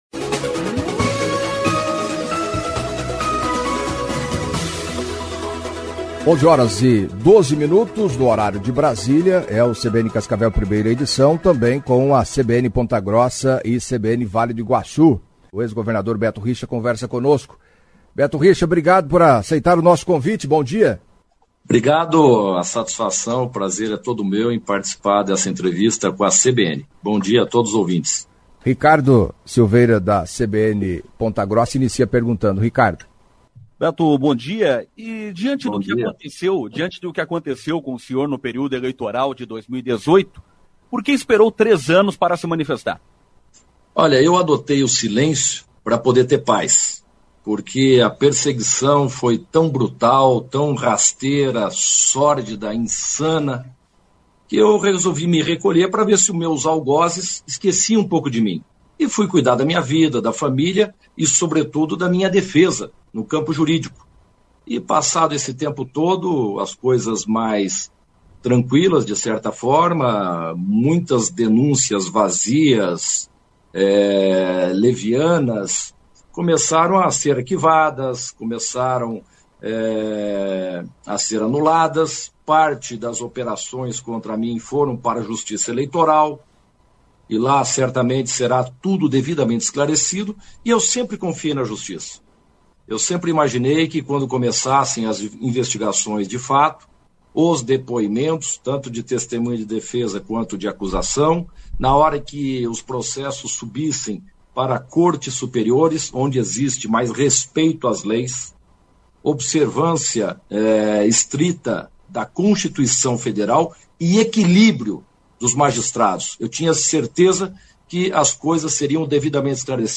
O ex-governador do Paraná Beto Richa, em entrevista à CBN Cascavel nesta terça-feira (09) em conexão com as CBNs Ponta Grossa e Vale do Iguaçu de União da Vitória, entre outros assuntos, disse que está em condição de participar do pleito em 2022.